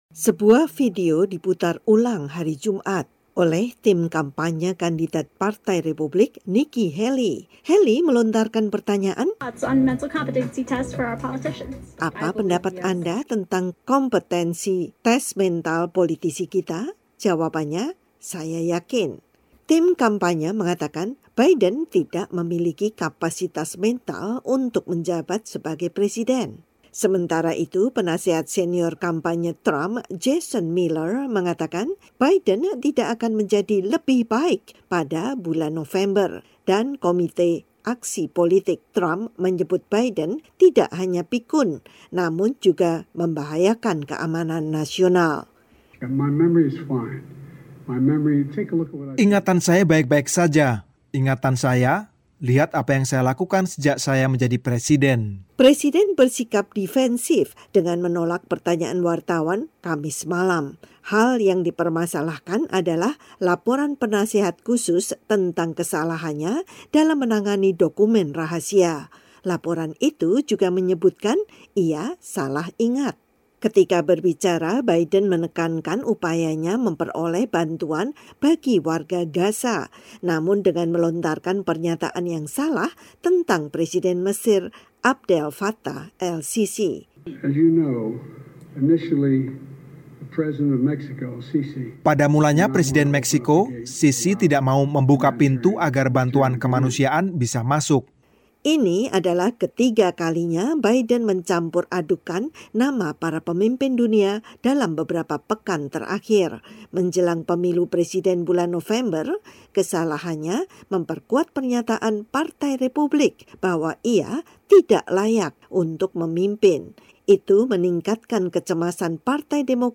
Berikut laporan VOA.